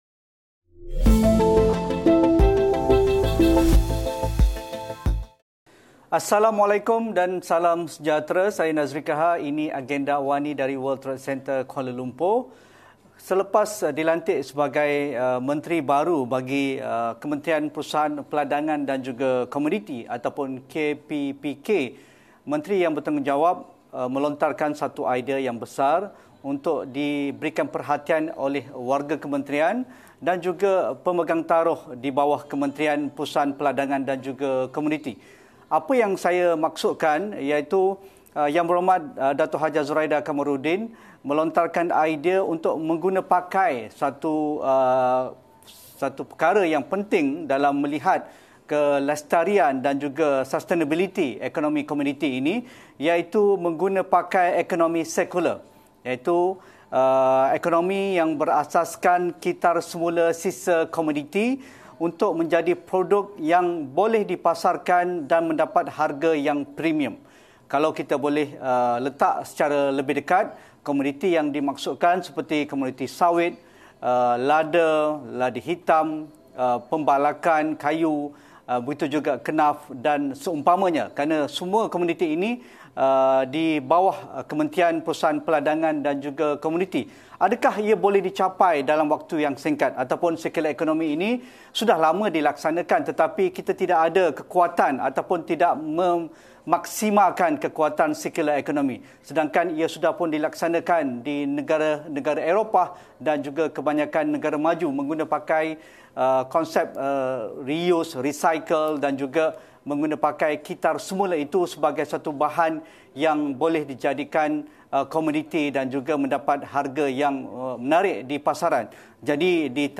Guna pakai ekonomi sirkular dalam kementeriannya, dengan fokus ke arah pengeluaran serta kitar semula sisa komoditi untuk menjadi produk yang boleh dipasarkan. Temu bual